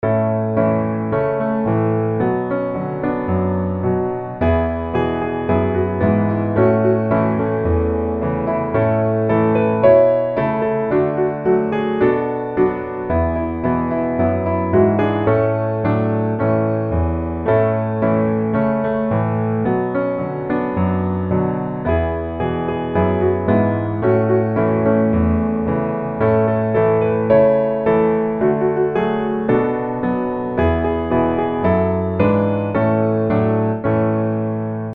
A大調